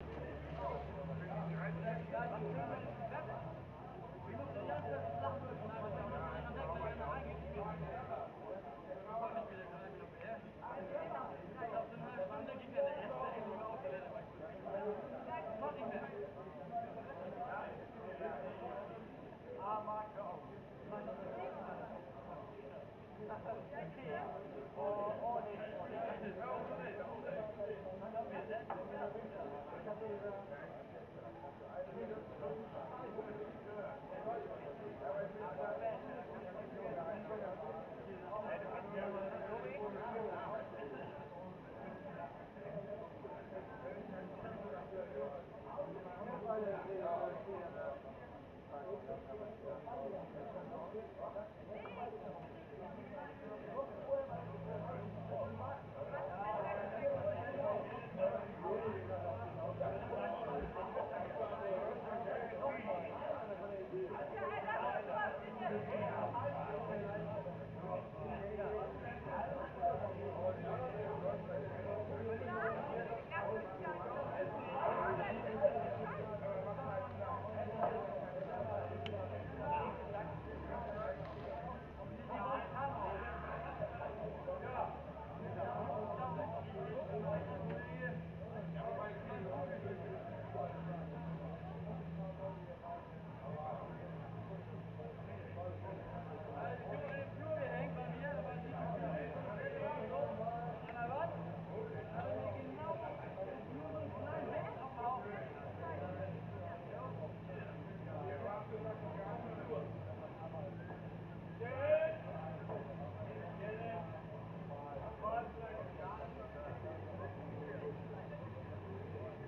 People talking loud in the distance at late night - Outside Ambience
ambience ambient atmosphere away celebrating celebration distance far sound effect free sound royalty free Nature